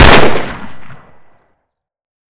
sol_reklam_link sag_reklam_link Warrock Oyun Dosyalar� Ana Sayfa > Sound > Weapons > GALIL Dosya Ad� Boyutu Son D�zenleme ..
WR_fire.wav